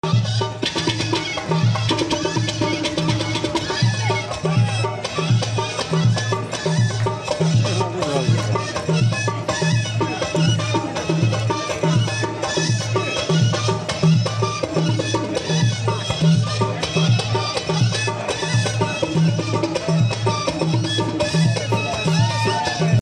أحواش العواد بمهرجان إسك إداوتنان